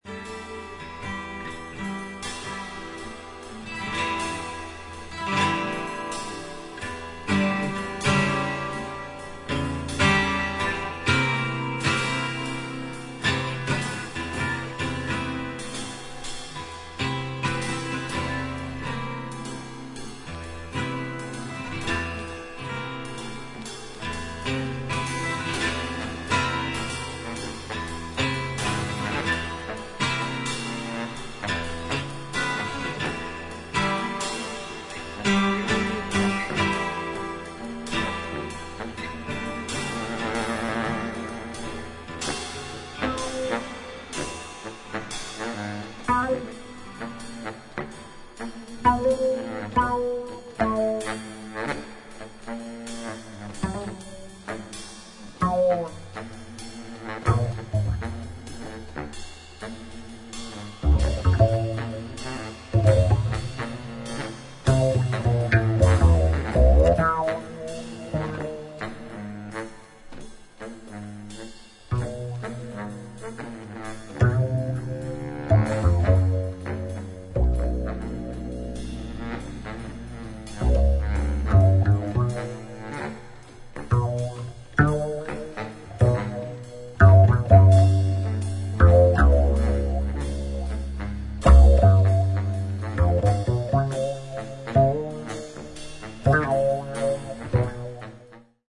サクソフォーン、インド竹笛、ボリビア笛、鳥笛、オカリナ etc.
ギター
ベース